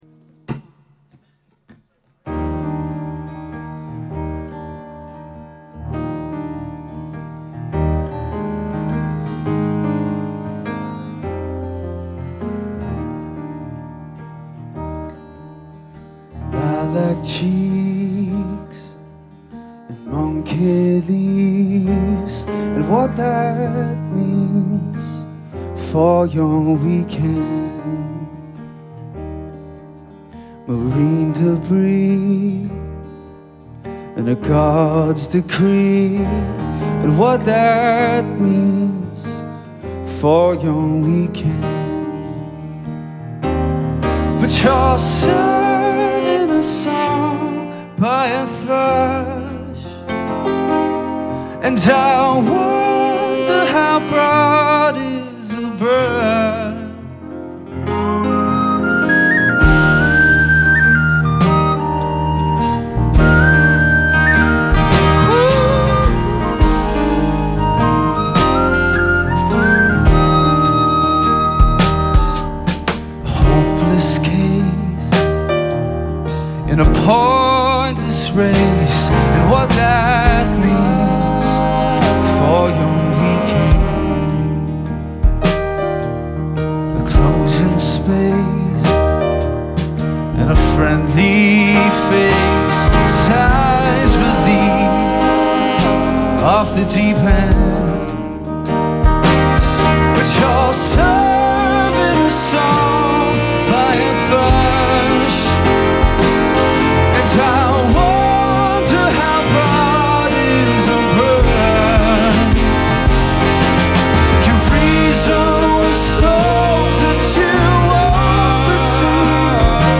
Alt Folk band
multi-instrumentalists and songwriting duo